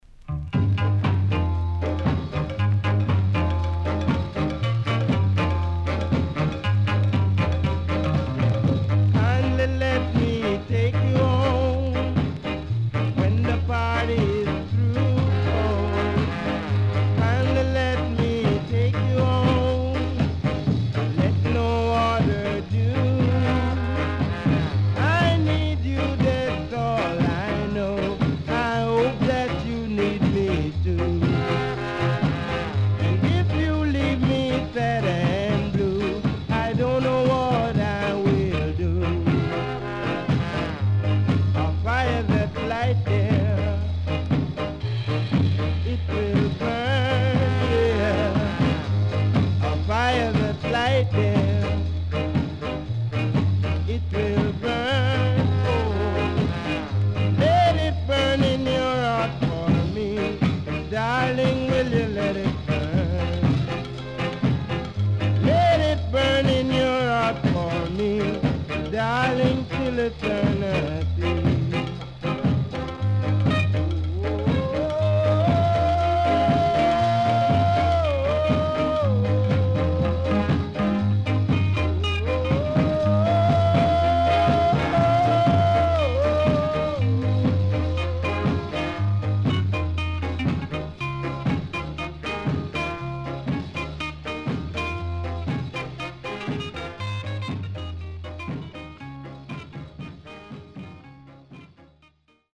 HOME > SKA / ROCKSTEADY  >  SKA
ギターの音色が印象的なVery Cool Ska.W-Side Good
SIDE A:所々チリノイズがあり、少しプチノイズ入ります。